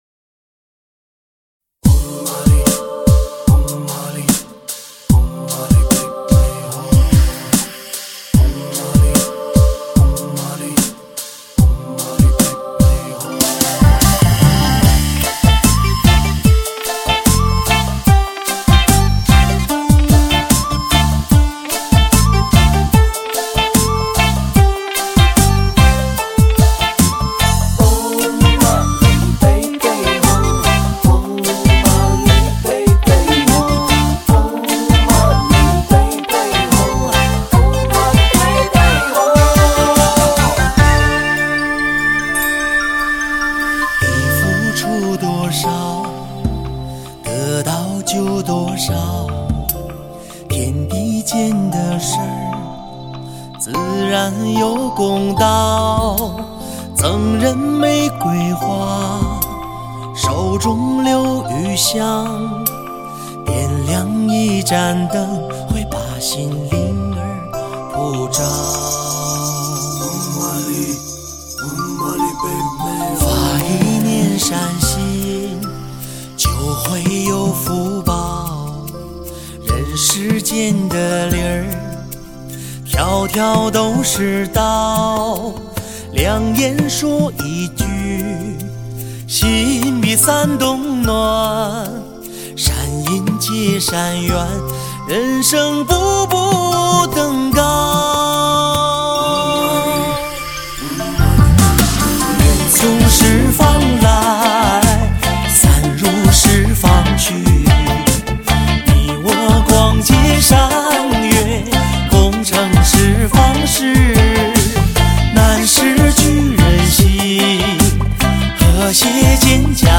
原创流行音乐合辑